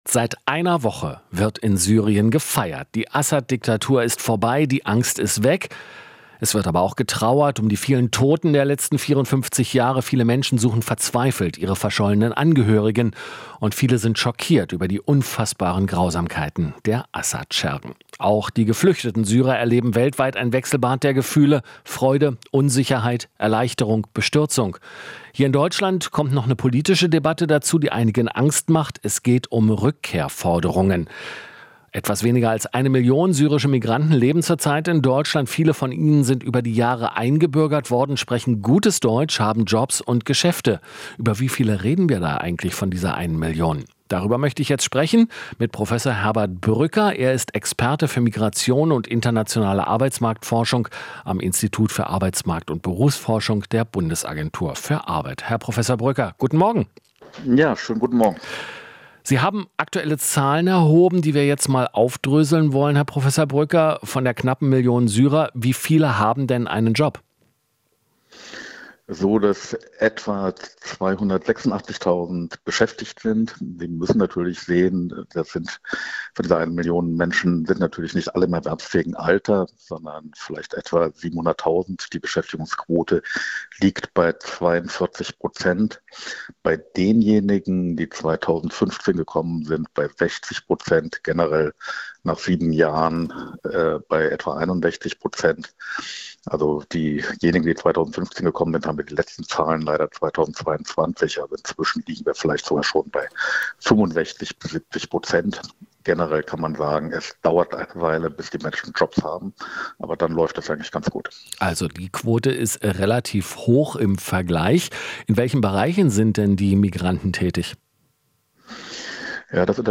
Interview - Arbeitsmarktforscher: Syrer arbeiten in systemrelevanten Berufen